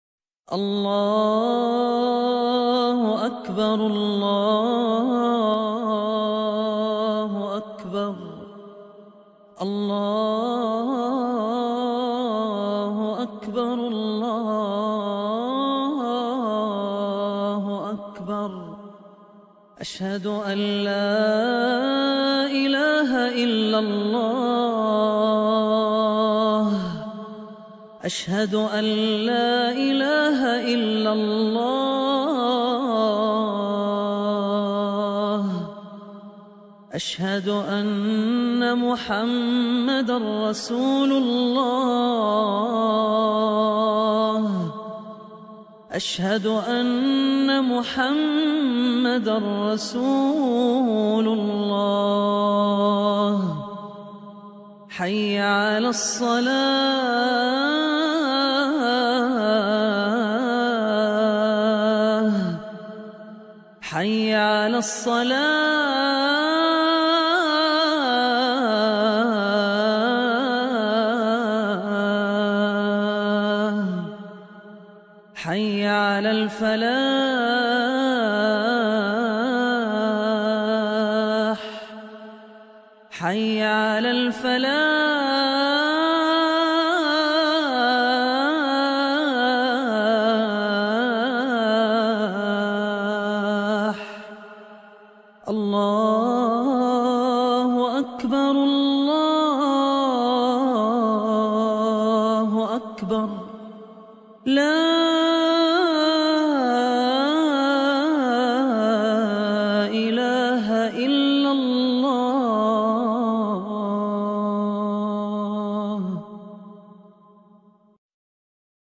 Download Beautiful Heart Touching Azan